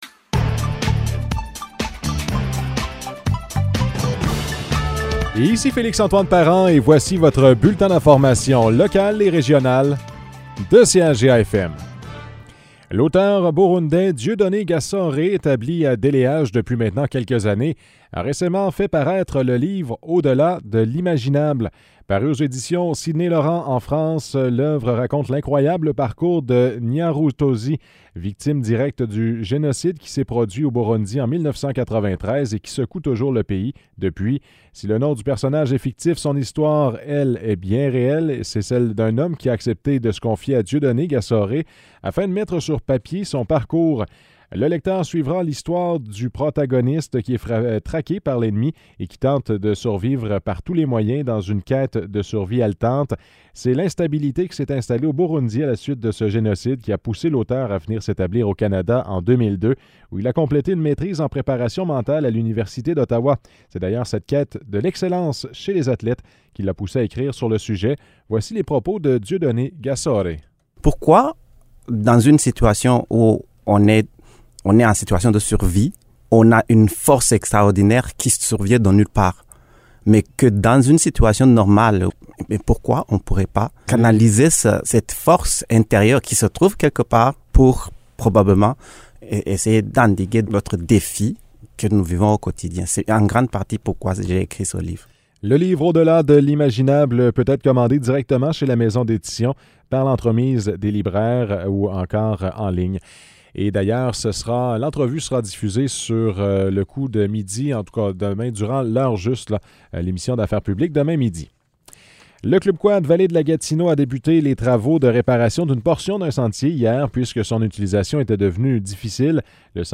Nouvelles locales - 30 novembre 2021 - 12 h